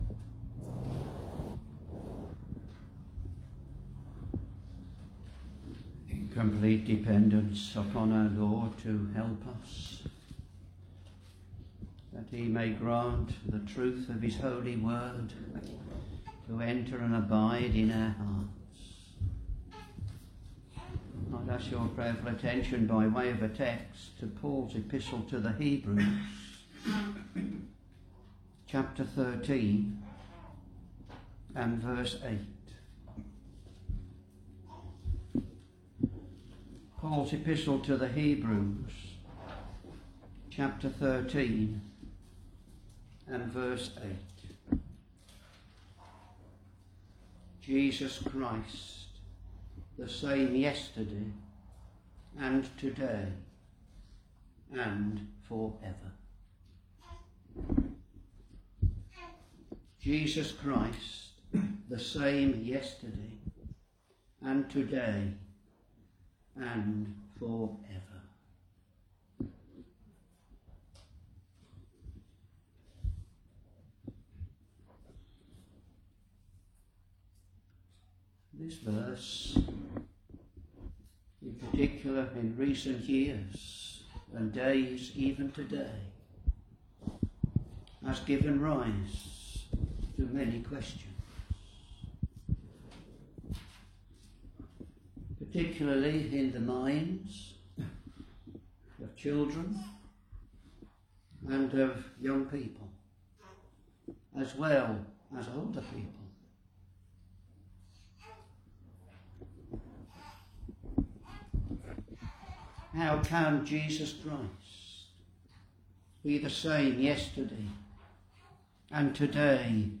Sermons Hebrews Ch.13. v.8 Jesus Christ the same yesterday, and to day, and for ever.